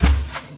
sounds / mob / cow / step2.ogg
step2.ogg